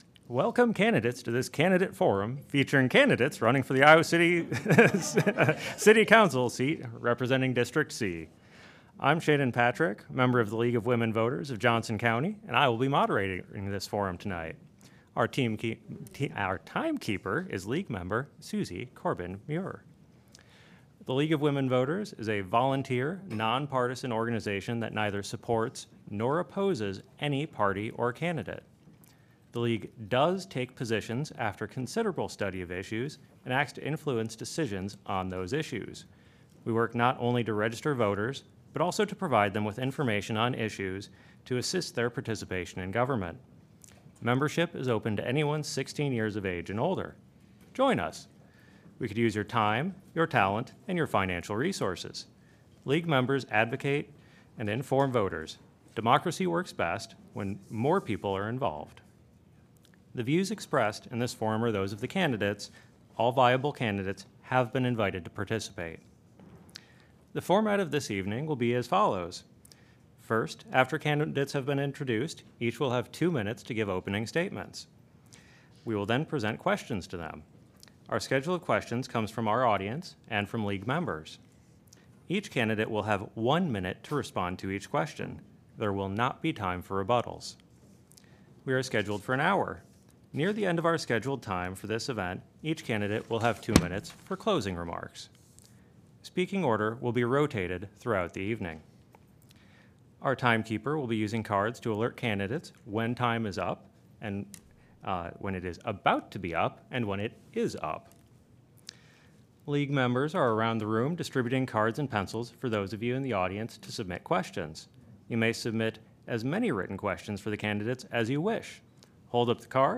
LWV Candidate Forum: 2025 City Council Special Election - January 27, 2025